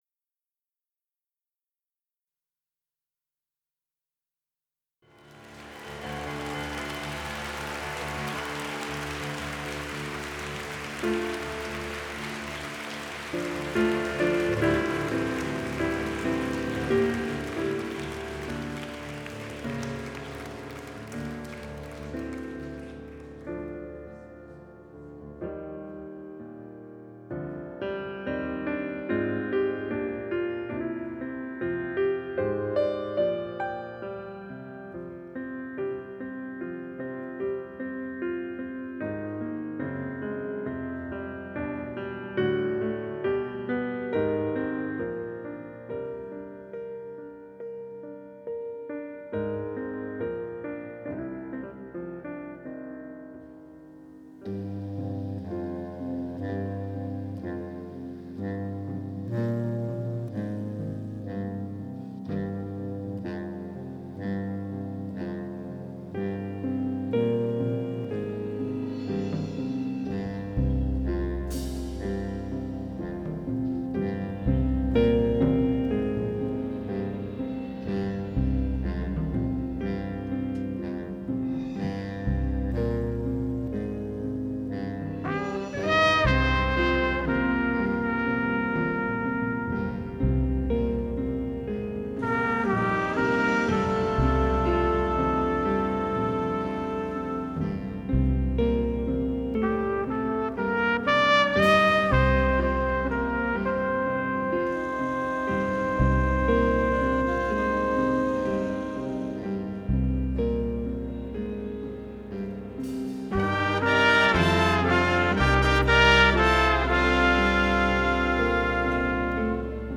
Тип альбома: Студийный
Жанр: Post-Bop
trumpet
trumpet, flugelhorn
trombone, tuba
alto saxophone, flute
tenor saxophone, clarinet, bass clarinet
keyboard
piano, Fender Rhodes, toy piano
bass
electric guitar
drums
percussion